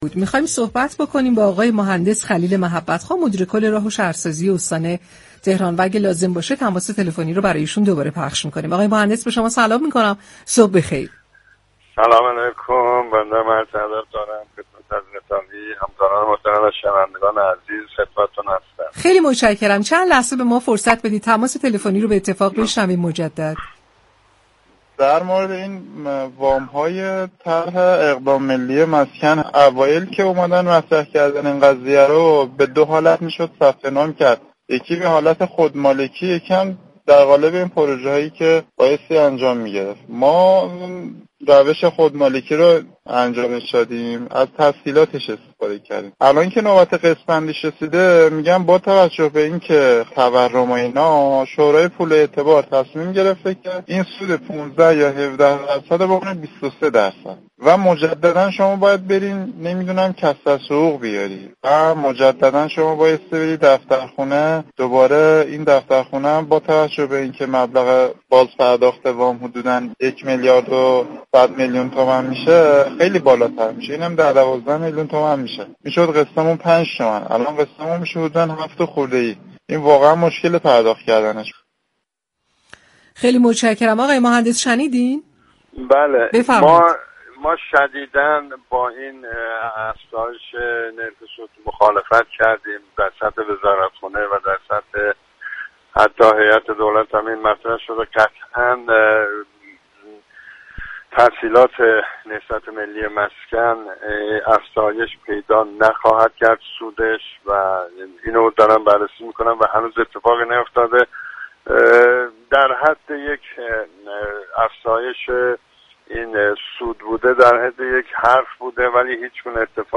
به گزارش پایگاه اطلاع رسانی رادیو تهران، خلیل محبت‌خواه مدیركل راه و شهرسازی استان تهران در گفت و گو با «شهر آفتاب» در پاسخ به پرسش یكی از شنوندگان برنامه مبنی بر اینكه تسهیلات طرح اقدام ملی مسكن دریافت كرده ولی متاسفانه سود این تسهیلات از 18 درصد به 23 درصد افزایش پیدا كرده است؛ درنتیجه مبلغ اقساط افزایش و پرداخت آن برایشان دشوار خواهد بود؛ اظهار داشت: به شدت با افزیش نرخ سود تسهیلات نهضت ملی مسكن مخالفت كردیم.